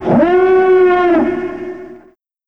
starwarsalarm.wav